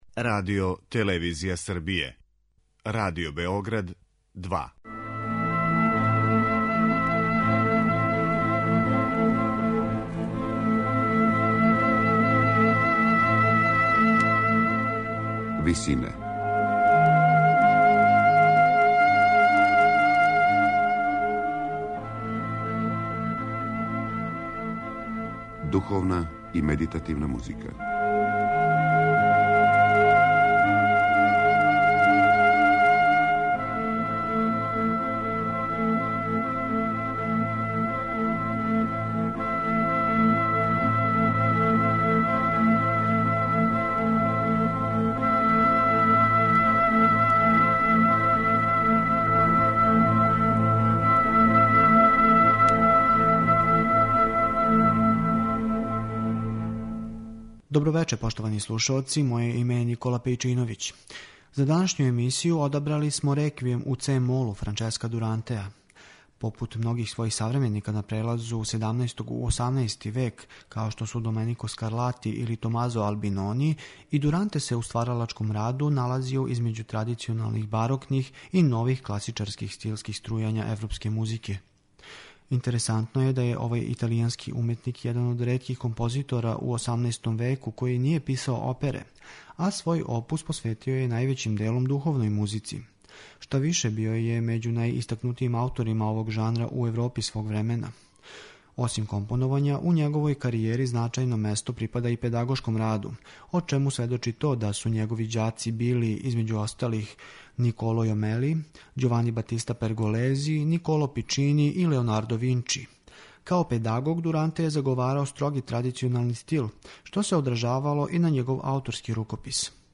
Вечерашње Висине посвећене су Реквијему у цe-молу италијанског композитора Франческа Дурантеа.
медитативне и духовне композиције